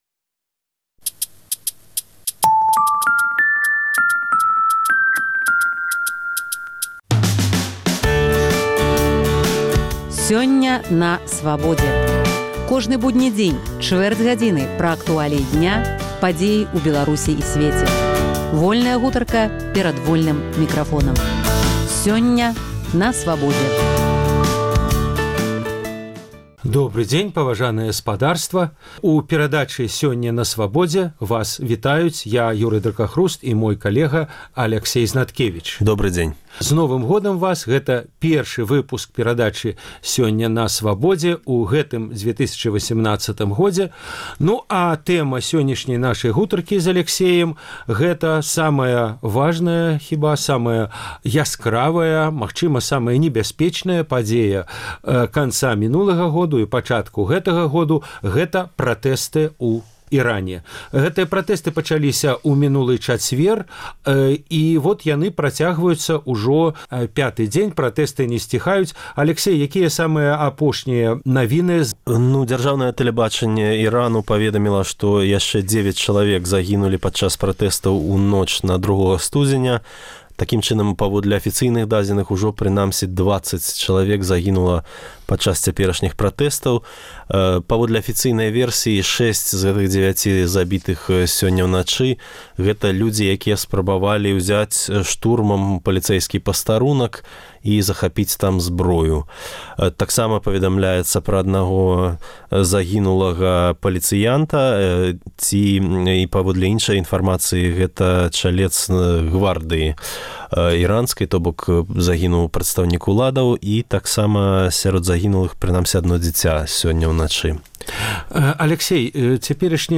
Гутарка пра храналёгію і прычыны пратэстаў у Іране